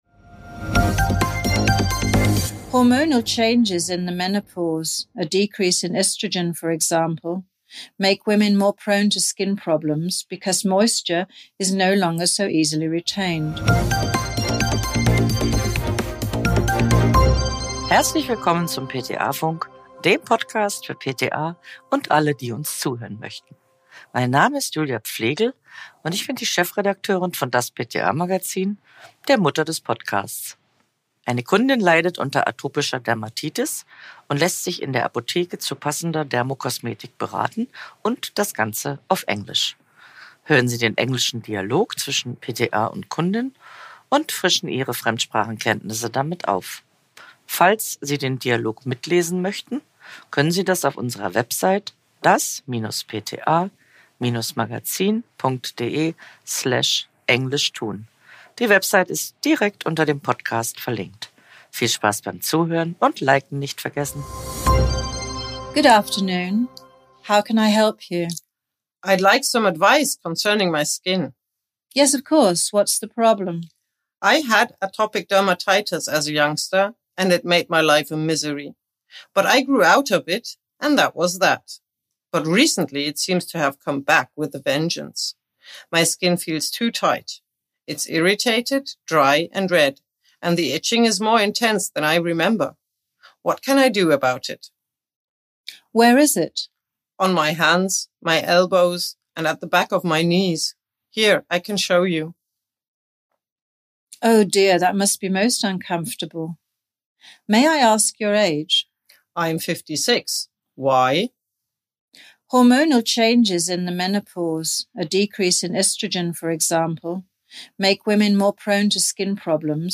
Hören Sie den Dialog zwischen PTA und Kundin und frischen Ihre